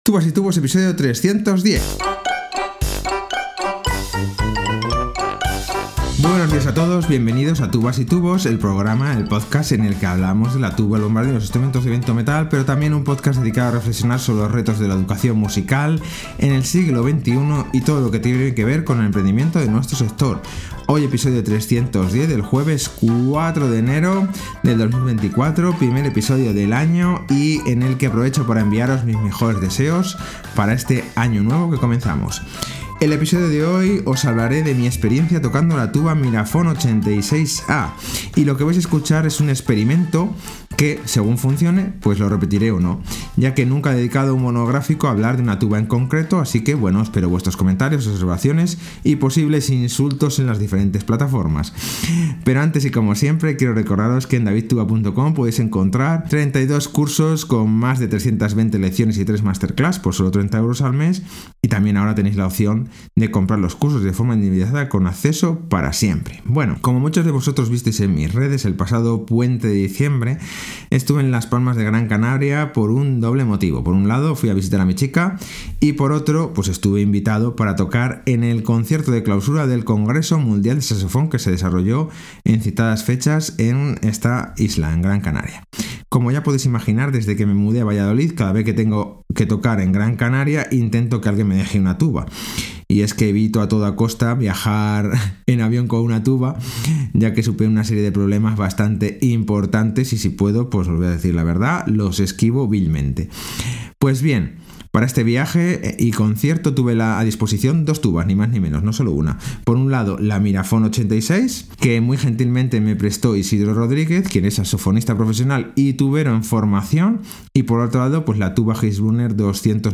310. Probando la tuba Miraphone 86